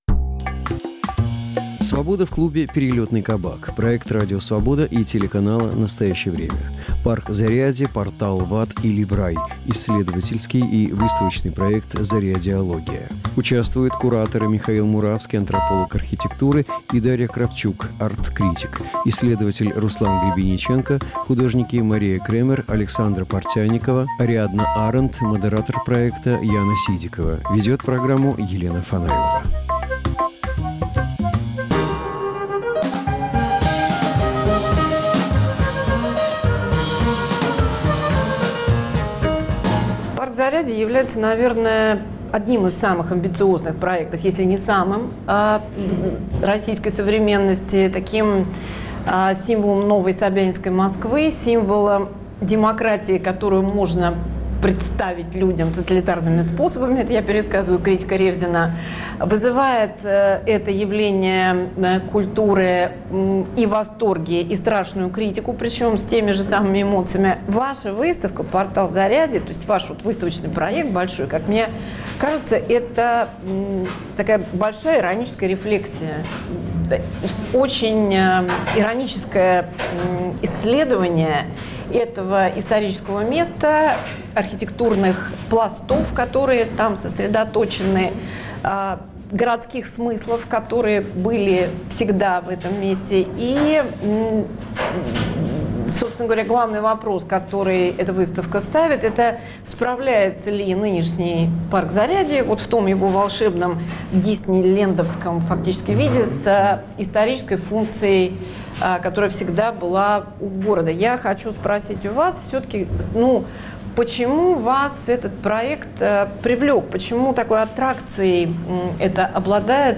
Стал ли парк Зарядье символом Москвы эпохи "управляемой демократии"? Разговор с исследователями архитектуры.